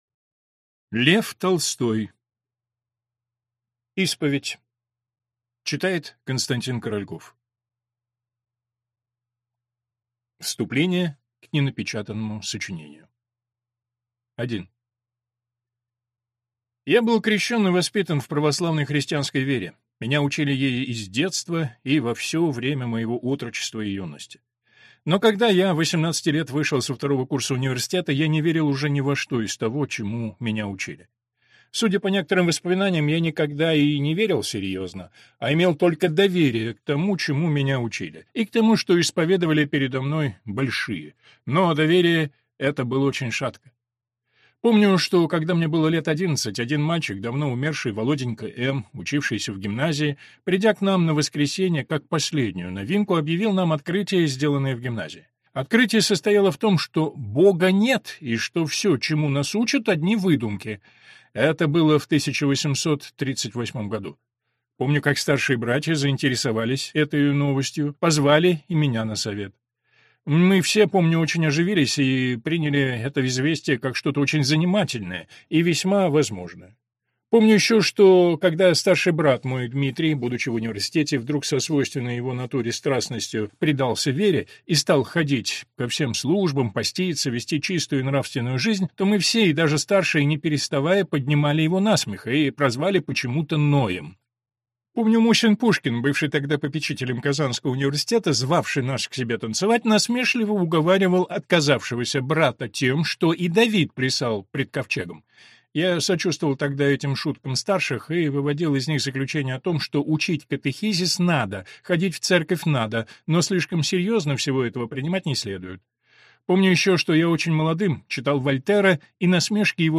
Аудиокнига Исповедь | Библиотека аудиокниг